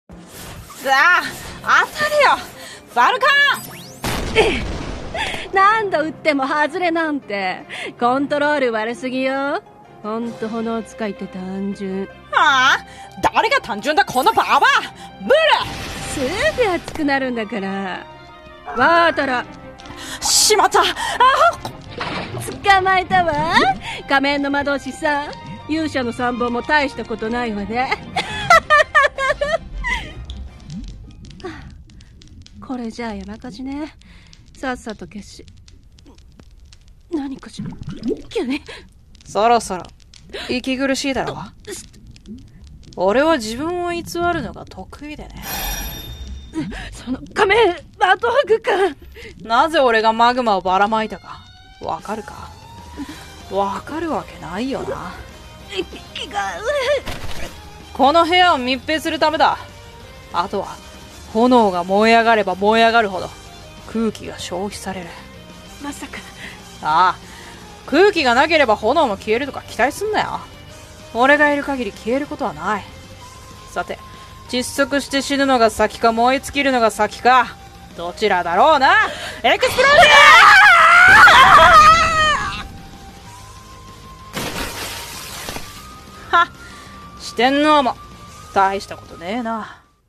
【戦闘系声劇台本】「対決（水と炎）」